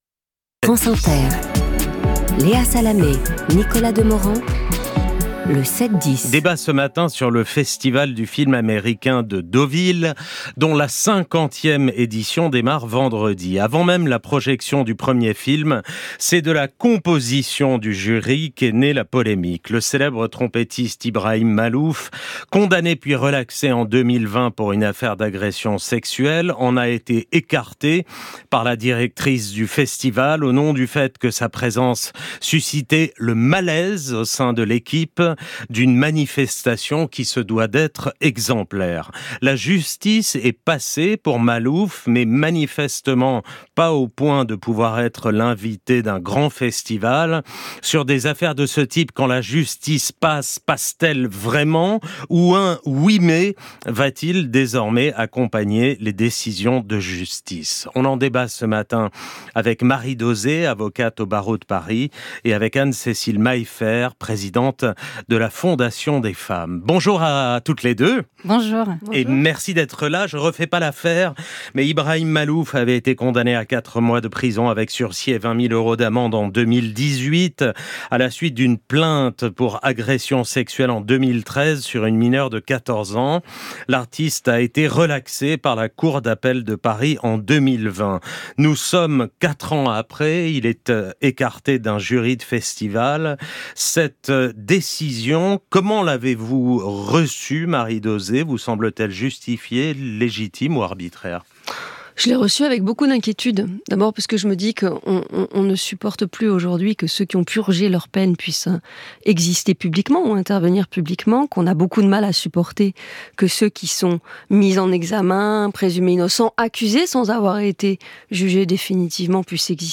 Le Débat du 7/10 – Présenté par Nicolas Demorand et Léa Salamé – Diffusé sur France Inter le 2 septembre 2024